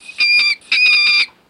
Mink 2 Screeches Quick